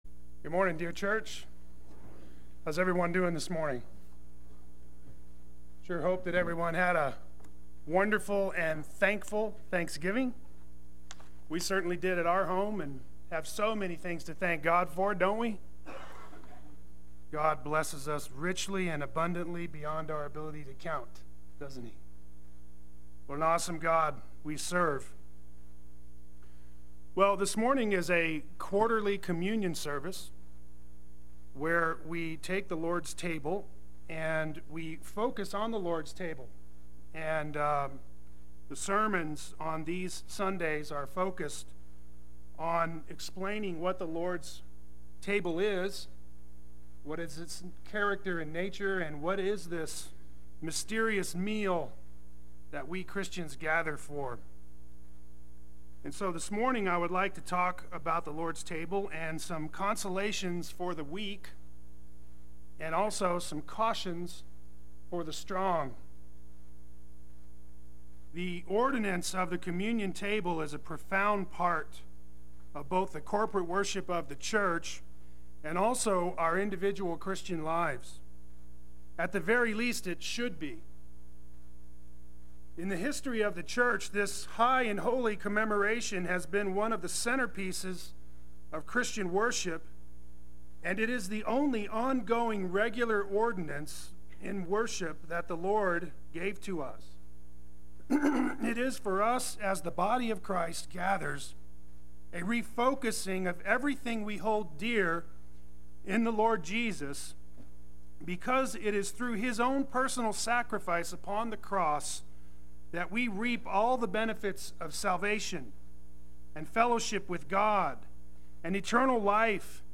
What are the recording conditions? Communion Sunday Worship